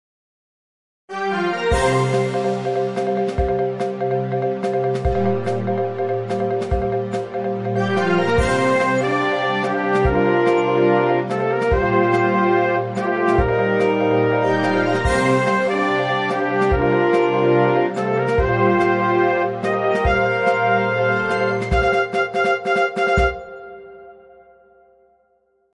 Download News sound effect for free.
Television News